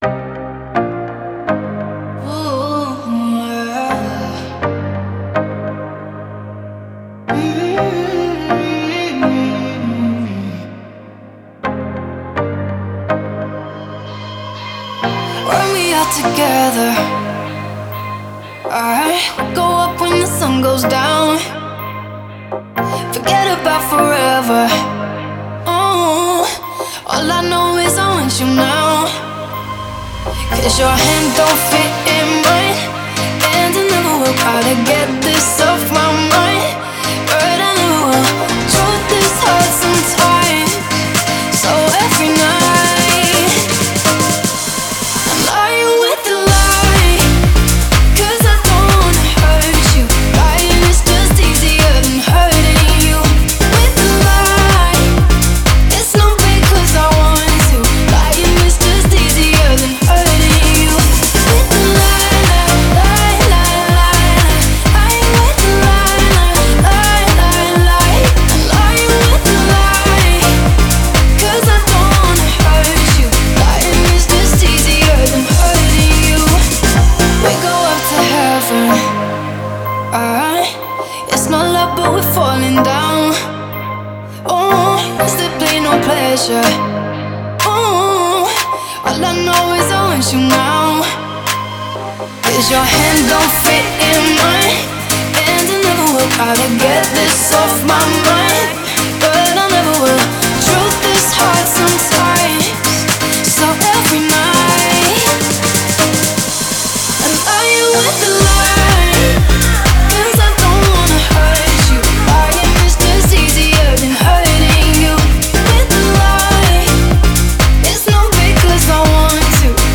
это энергичная электронная композиция в жанре house